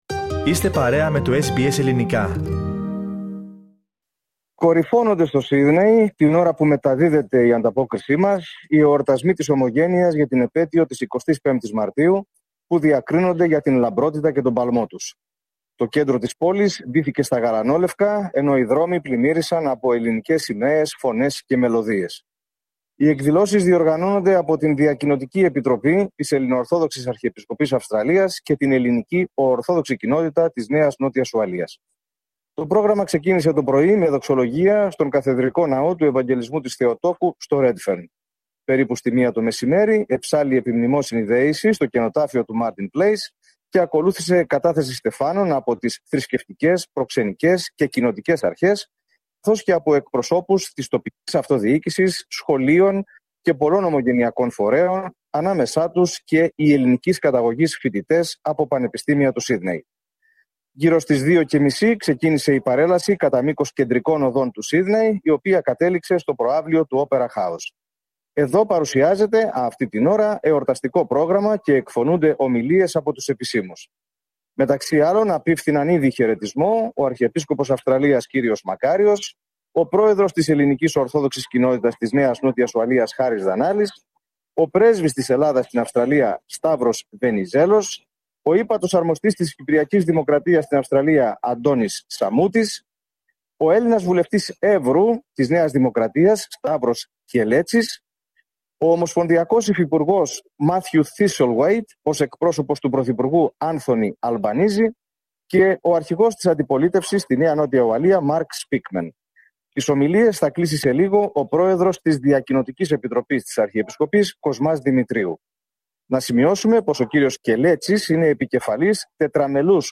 Greek National Day Parade, Sydney Opera House, 23 March 2025